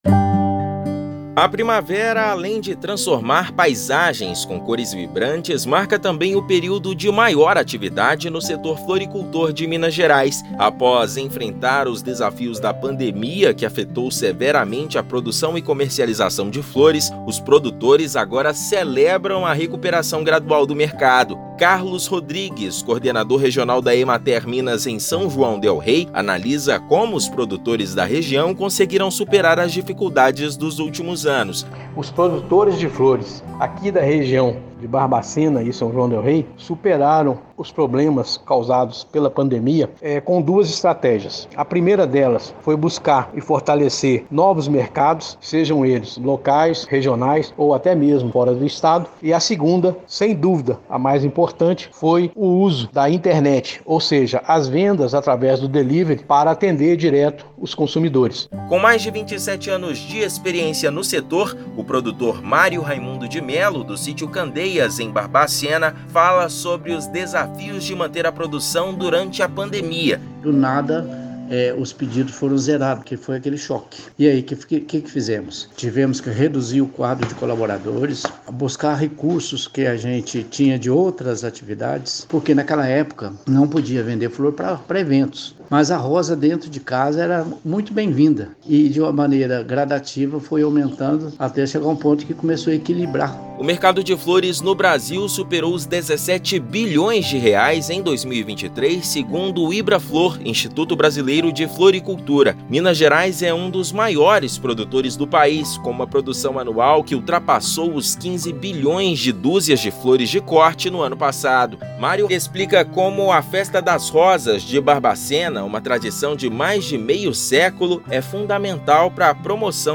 [RÁDIO] Produtores de flores de Minas comemoram aquecimento do setor
Festa das Rosas, em Barbacena, abre temporada de grande procura por espécies de corte e raiz. Ouça matéria de rádio.